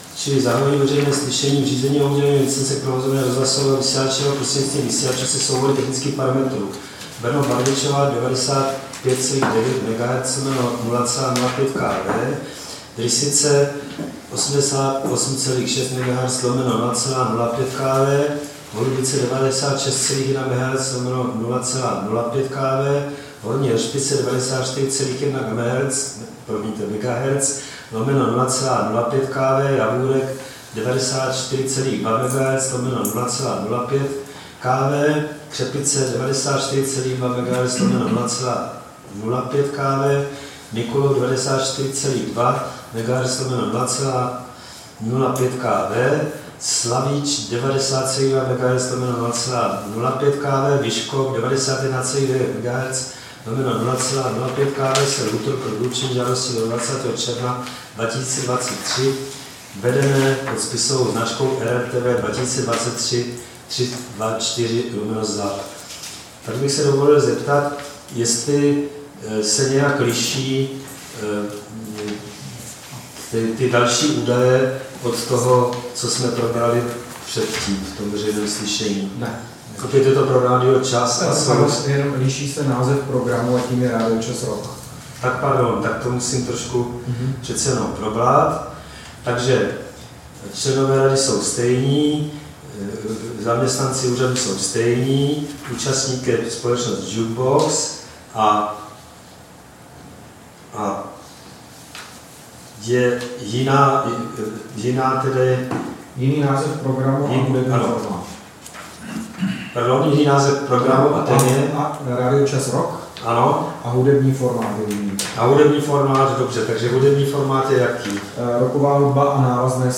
Veřejné slyšení v řízení o udělení licence k provozování rozhlasového vysílání šířeného prostřednictvím vysílačů se soubory technických parametrů Brno-Barvičova 95,9 MHz/50 W, Drysice 88,6 MHz/50 W, Holubice 96,1 MHz/50 W, Horní Heršpice 94,1 MHz/50 W, Javůrek 94,2 MHz/50 W, Křepice 94,2 MHz/50 W, Mikulov 94,2 MHz/50 W, Slavíč 90,2 MHz/50 W, Vyškov 91,9 MHz/50 W
Místem konání veřejného slyšení je sídlo Rady pro rozhlasové a televizní vysílání, Škrétova 44/6, 120 00 Praha 2.
3. Veřejné slyšení zahajuje, řídí a ukončuje předseda Rady nebo jím pověřený člen Rady.
1. Úvodem bude osoba jednající jménem žadatele požádána, aby se představila, uvedla svou funkci a název žadatele.